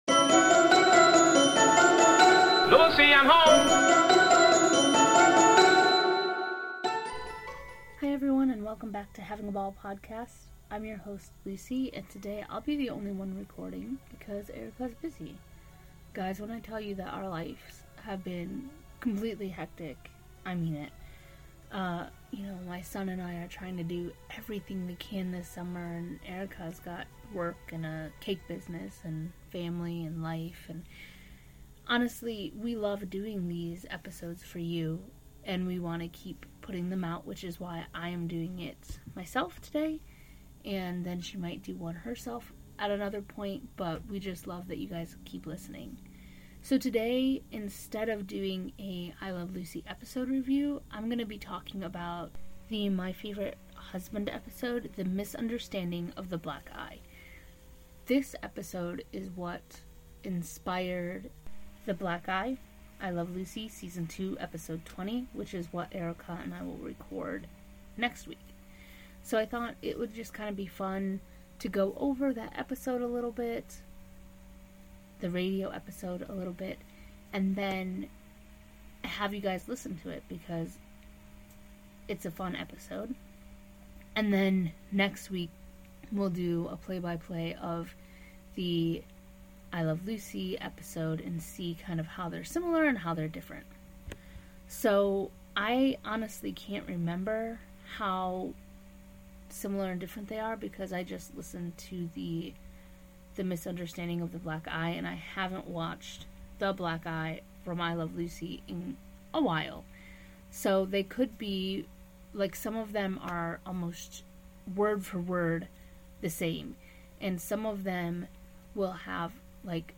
You will then hear the episode as it was played in 1951.